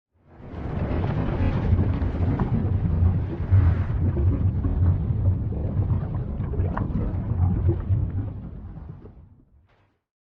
Lava Gurgle
Lava Gurgle is a free sfx sound effect available for download in MP3 format.
yt_HQR_uLMH5NA_lava_gurgle.mp3